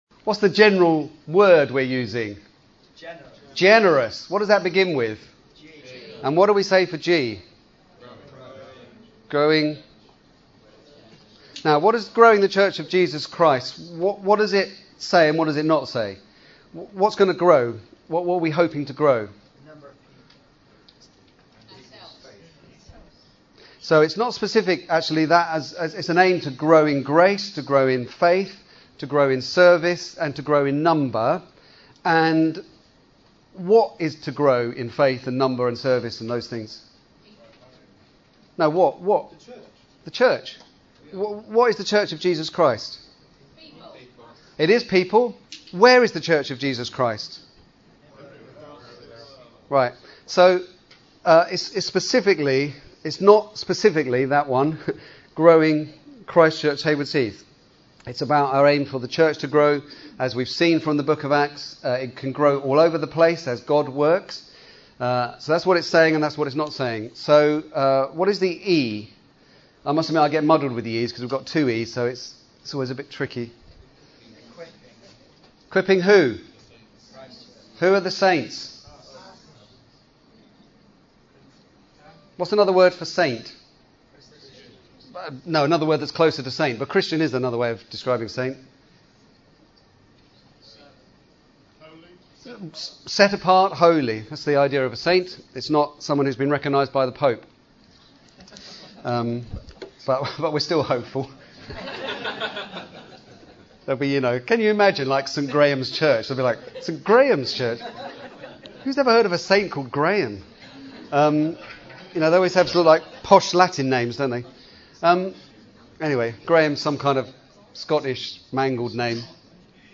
Here are some of the recordings from the teaching and presentations over the weekend along with some of the slides that we talked through.